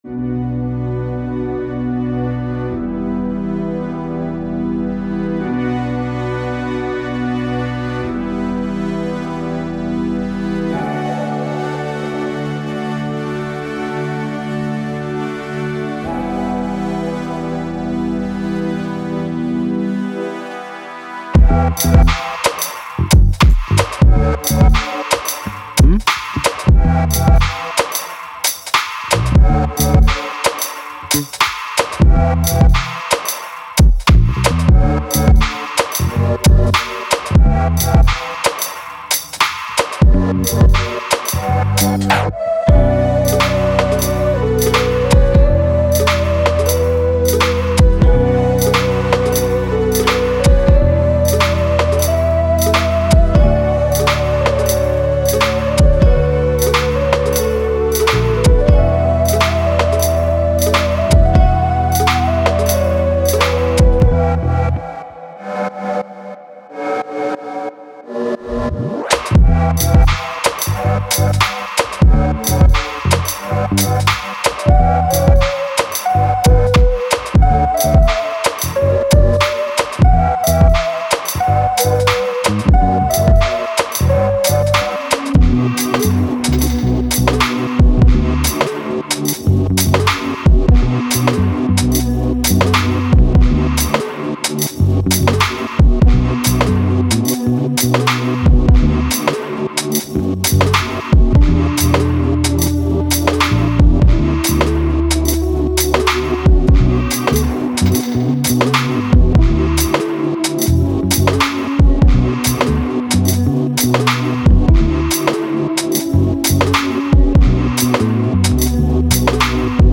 it’s a narrative without words.
Genres: Hip-hop and rap